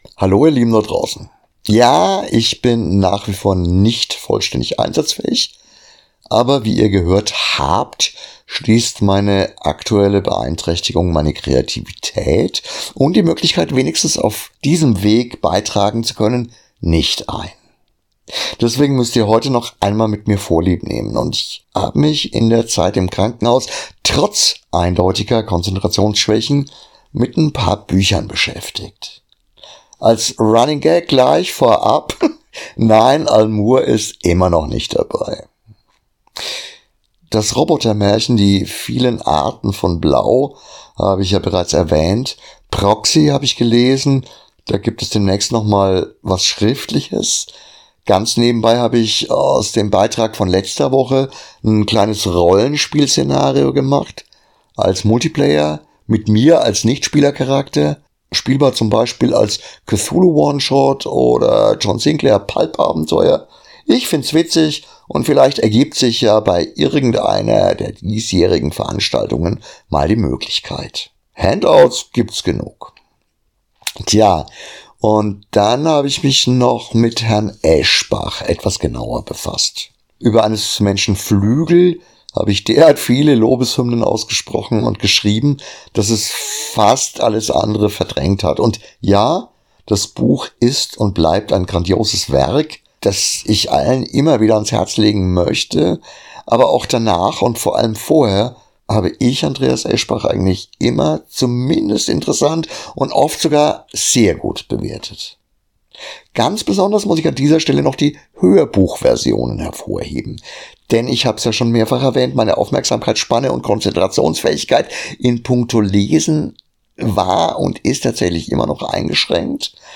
Als Abschluss meiner Arbeitsunfähigkeits-Reihe aus dem Krankenhaus, hört ihr heute noch einen letzten Beitrag von mir solo. Ich habe ein paar Lese oder in diesem Fall sogar explizit Hörbuch Tipps, aus Gründen, die mit den derzeitigen Umständen zu tun haben. Außerdem nehme ich Andreas Eschbach als Vorwand um ein selbst auferlegtes Tabu zu brechen.